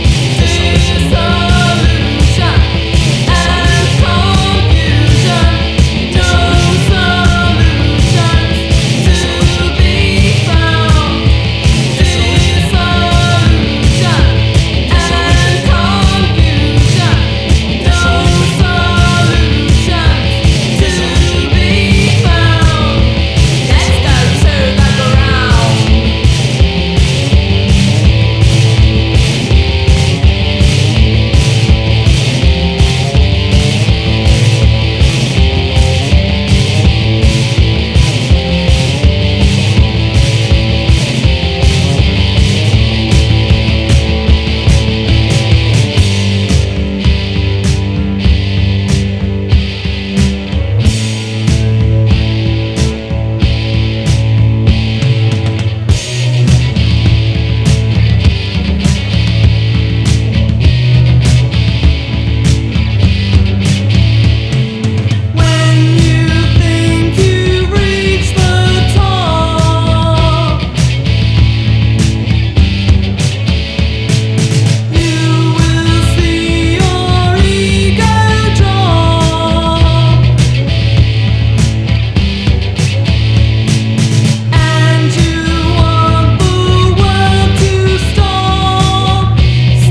SYMPHONIC ROCK / PROGRESSIVE ROCK / JAZZ ROCK
ではリリカルなピアノと木管が溶け合い
ではミニマル的な構築美と躍動感あるアンサンブルがスケール豊かに展開。